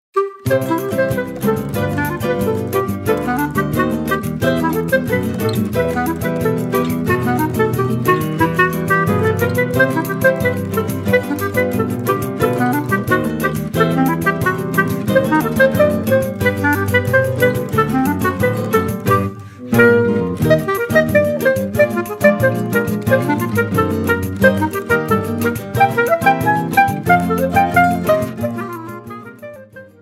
clarinete